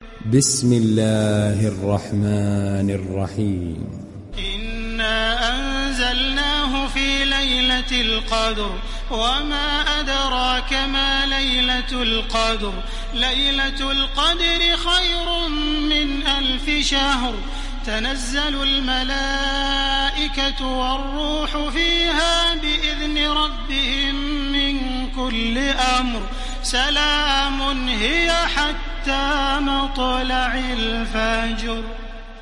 Sourate Al Qadr Télécharger mp3 Taraweeh Makkah 1430 Riwayat Hafs an Assim, Téléchargez le Coran et écoutez les liens directs complets mp3
Télécharger Sourate Al Qadr Taraweeh Makkah 1430